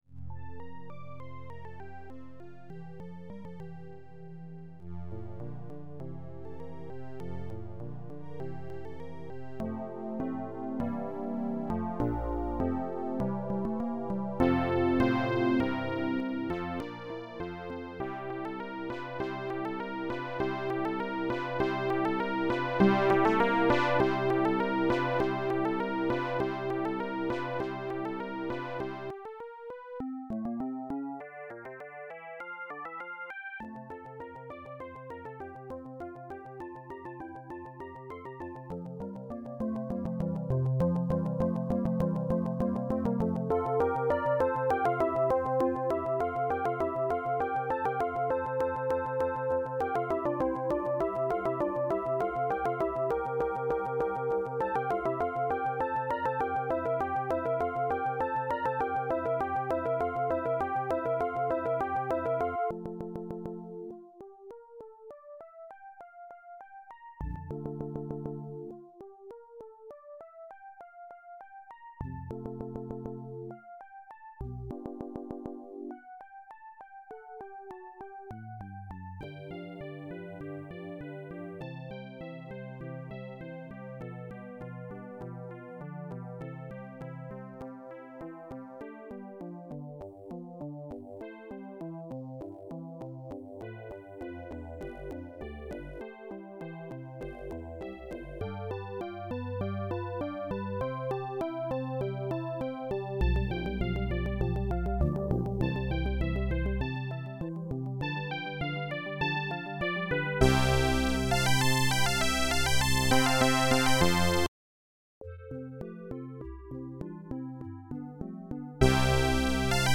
AdLib/Roland Song
Instruments strng-k clrnt-k trumpet4 oboe-k oboe1 flute1 frhrn-k sax1 trumpet1 flute vln-k bassoon1 cbbsn-k frhorn1 bssn-k enghrn-k cbassoon tromb1 bass1 piano1 clarinet tuba1